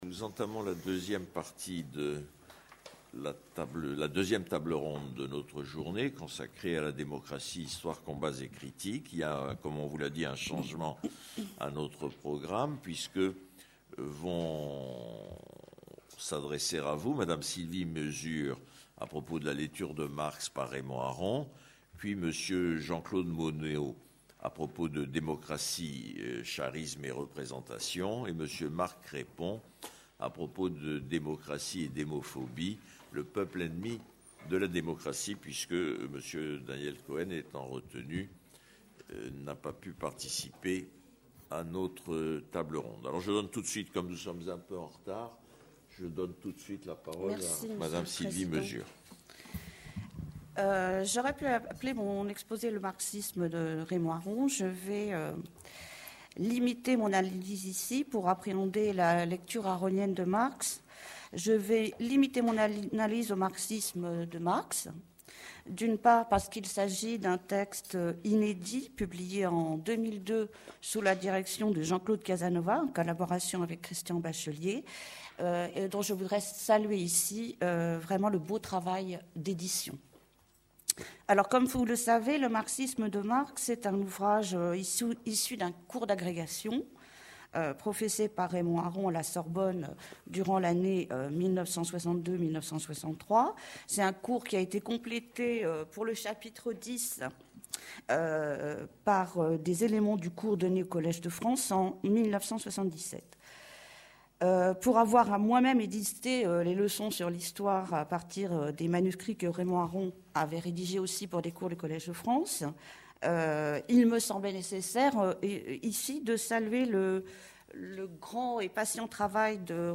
Démocratie : histoire, combats, critiques: 2e table ronde : Démocratie et société post-industrielle | Canal U